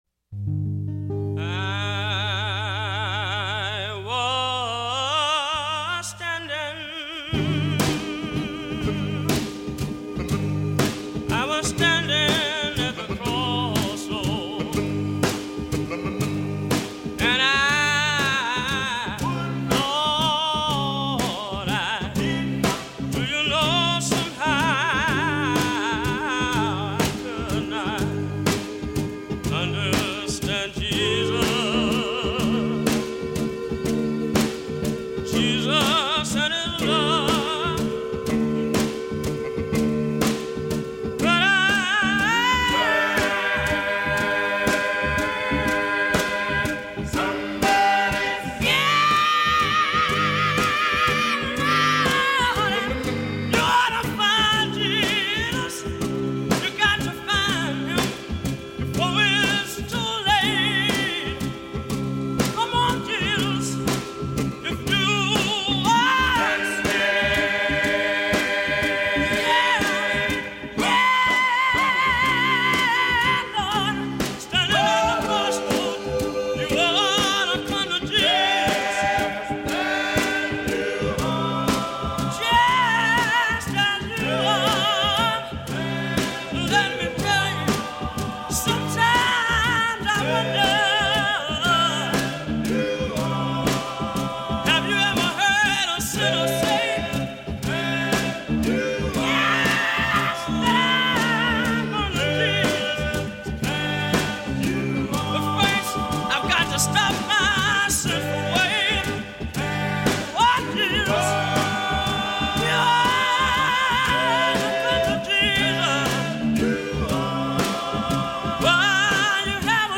Scratchy vanity 45s, pilfered field recordings, muddy off-the-radio sounds, homemade congregational tapes and vintage commercial gospel throw-downs; a little preachin', a little salvation, a little audio tomfoolery.